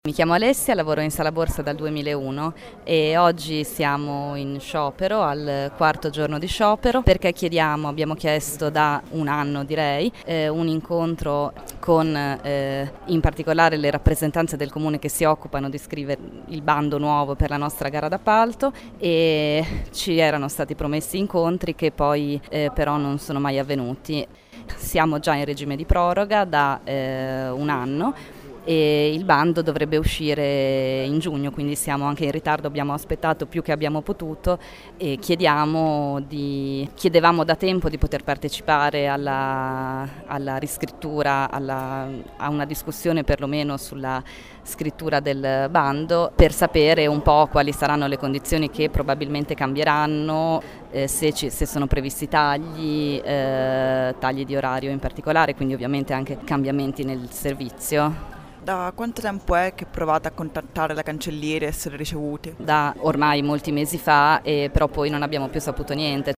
Ascolta i lavoratori